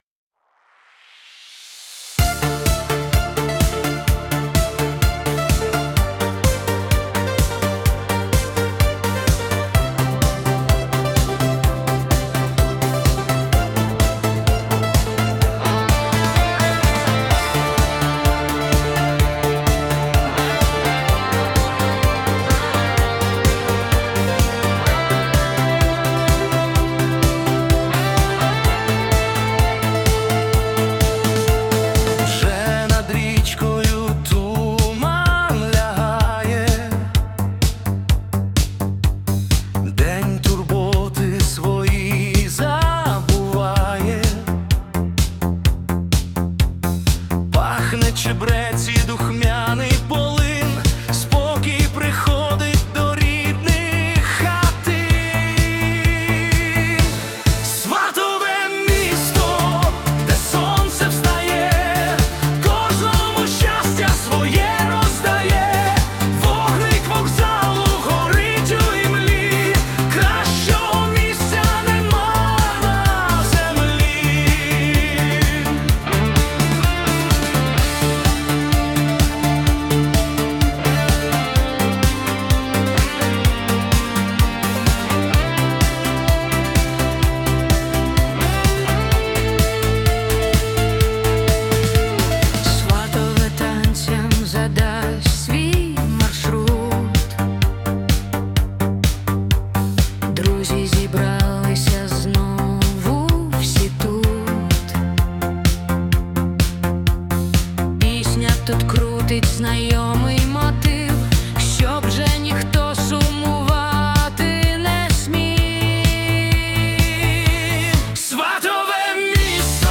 🎵 Жанр: Євро-диско / Ретро-поп